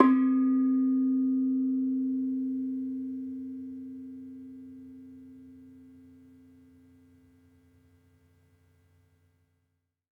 Sound Banks / HSS-Gamelan-1 / Bonang / Bonang-C3-f.wav
Bonang-C3-f.wav